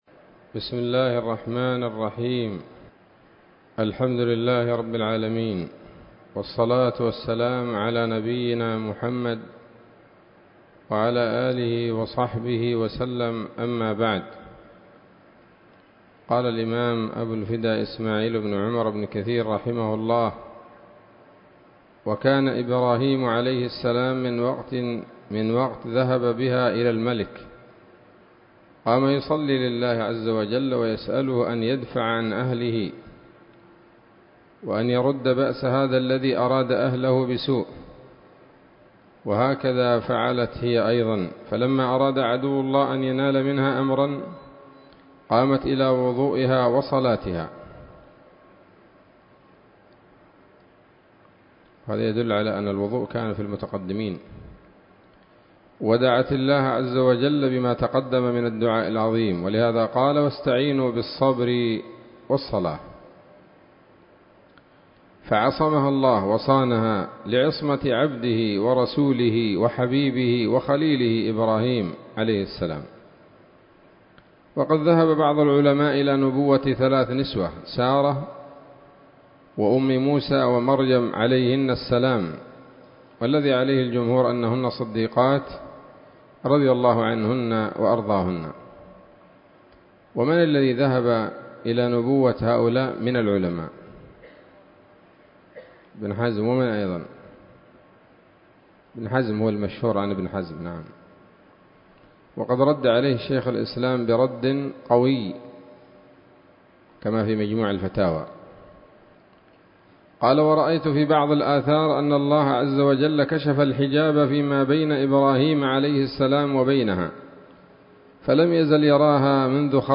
الدرس السادس والأربعون من قصص الأنبياء لابن كثير رحمه الله تعالى